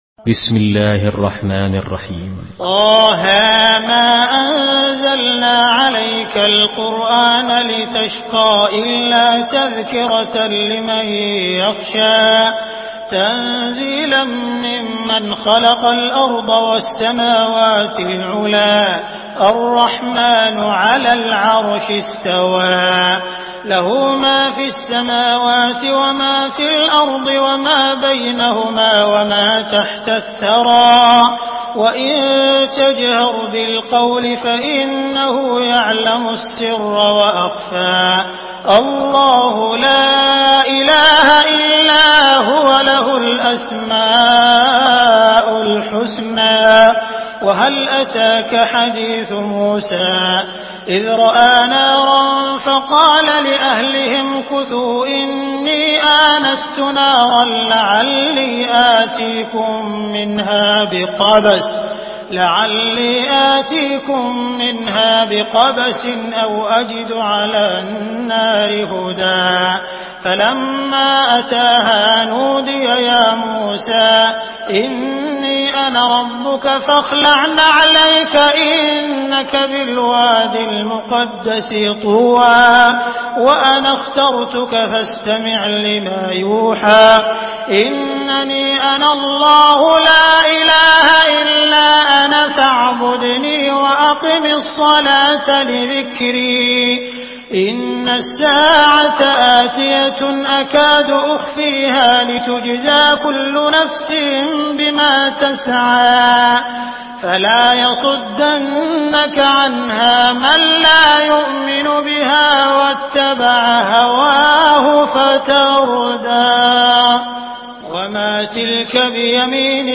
Surah Taha MP3 in the Voice of Abdul Rahman Al Sudais in Hafs Narration
Murattal Hafs An Asim